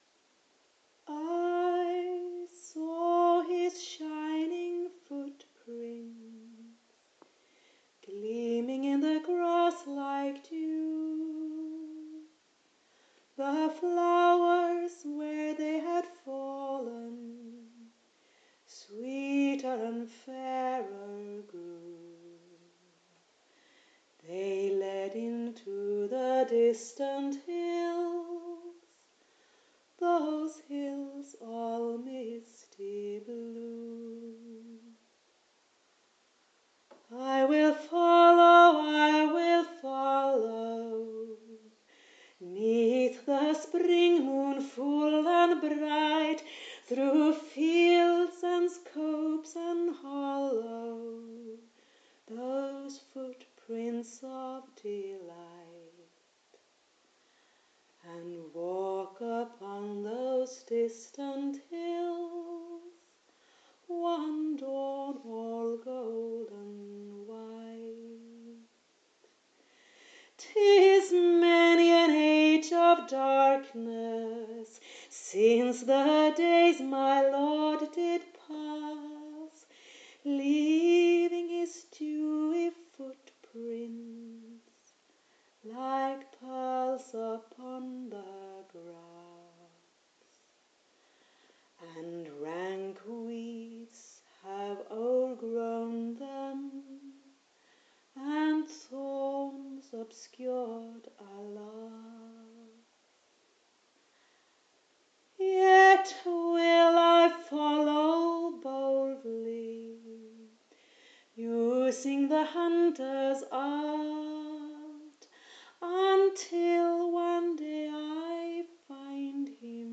singing
to music she composed herself